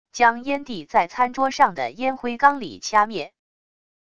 将烟蒂在餐桌上的烟灰缸里掐灭wav音频